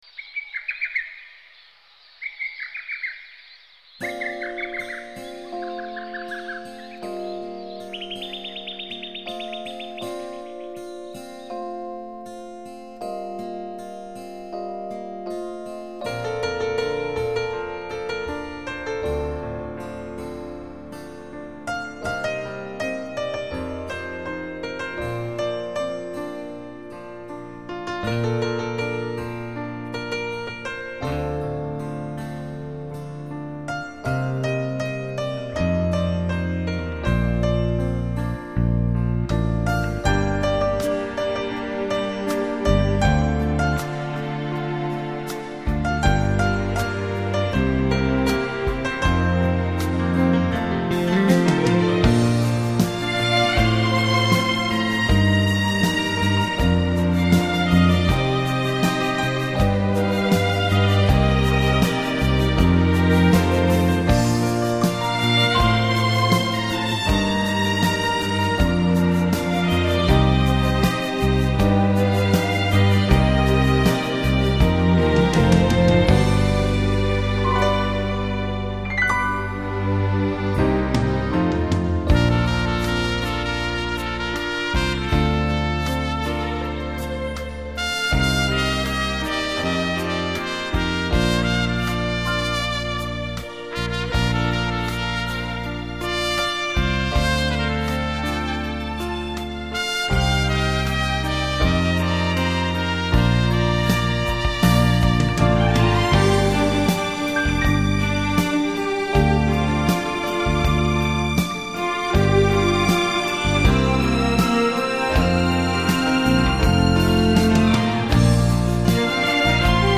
音乐类别: 纯音乐,New Age, Various